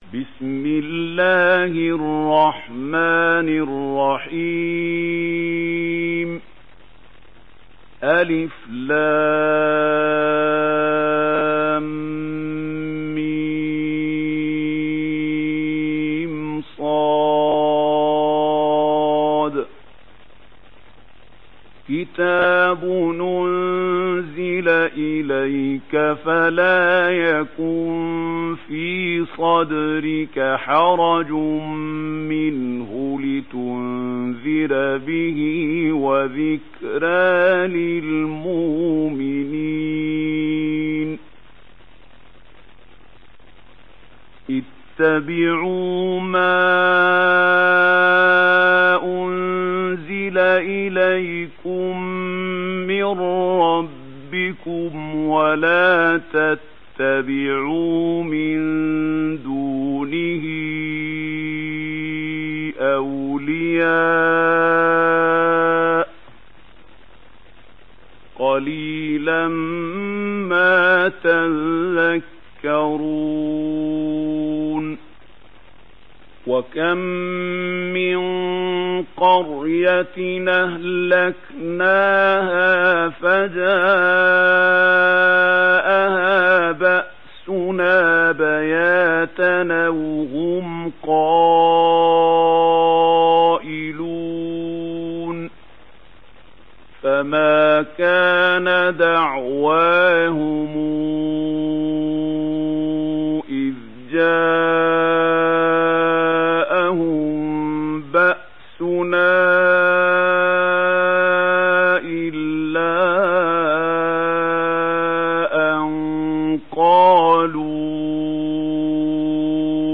دانلود سوره الأعراف mp3 محمود خليل الحصري روایت ورش از نافع, قرآن را دانلود کنید و گوش کن mp3 ، لینک مستقیم کامل